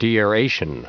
Prononciation du mot deaeration en anglais (fichier audio)
Prononciation du mot : deaeration